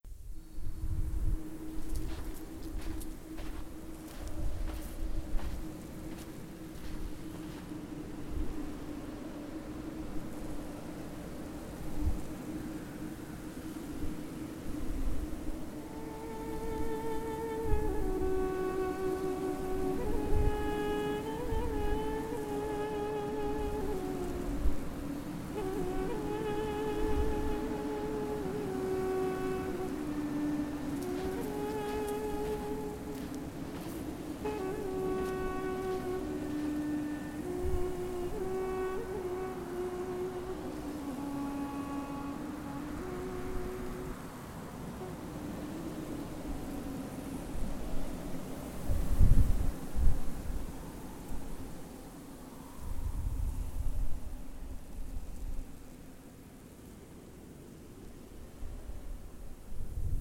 Ancient Egypt Ambient Audio
Ancient Egypt ambient audio sets the scene for your escape room or haunt scene.